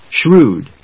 /ʃrúːd(米国英語), ʃru:d(英国英語)/